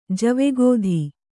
♪ jave gōdhi